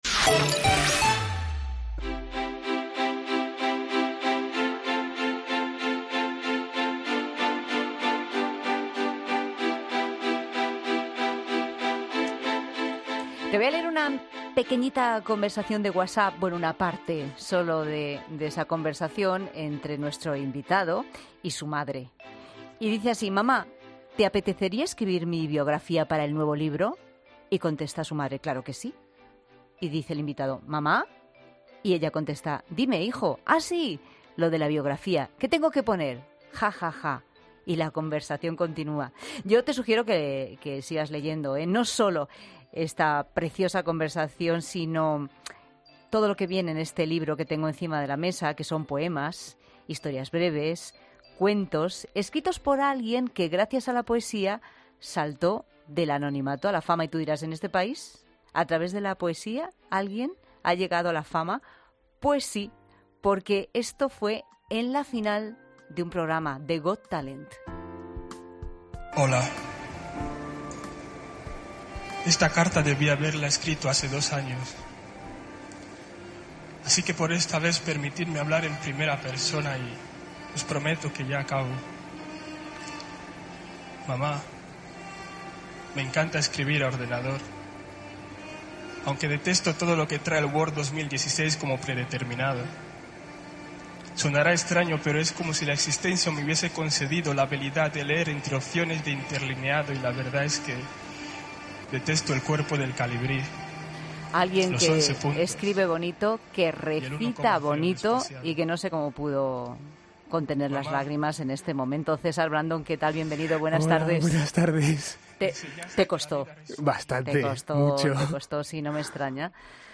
César Brandon, el ganador de 'Got Talent' recita poesía en 'La Tarde'